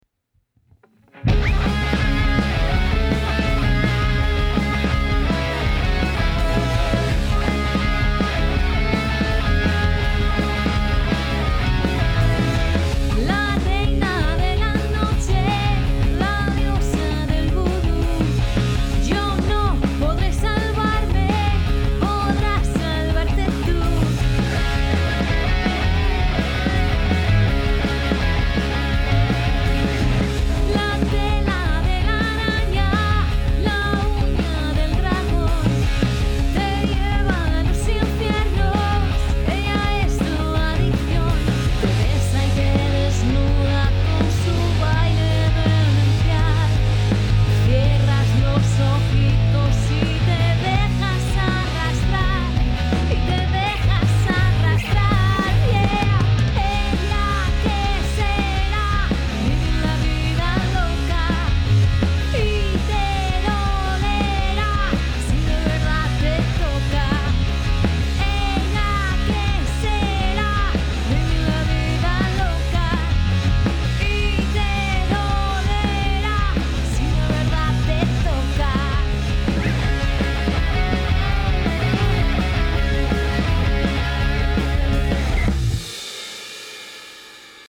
Banda de Versiones.